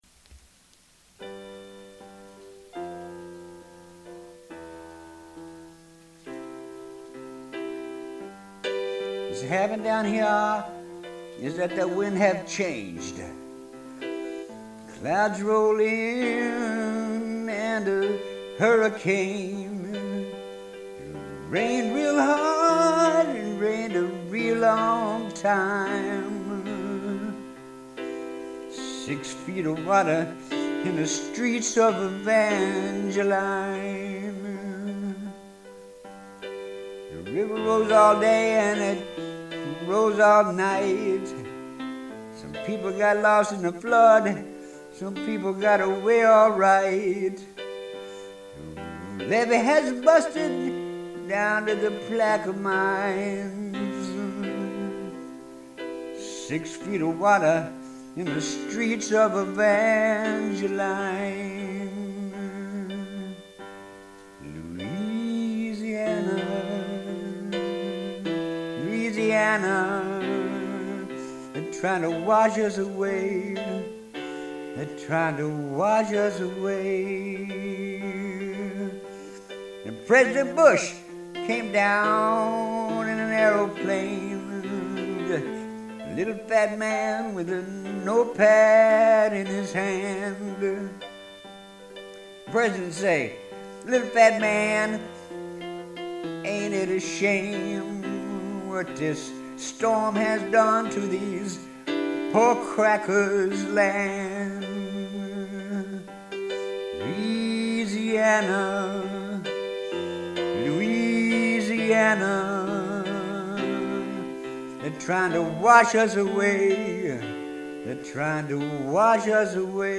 here's a tune I recorded the last time this happened
sorry about the lousy recording